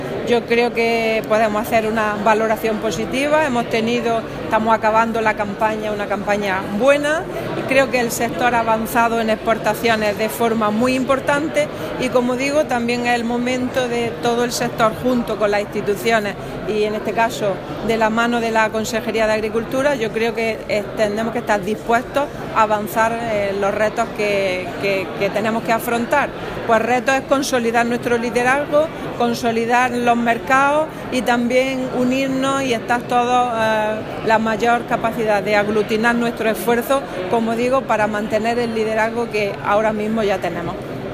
Declaraciones de Carmen Ortiz sobre el sector oleícola andaluz